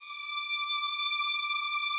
strings_074.wav